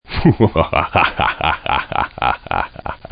Laugh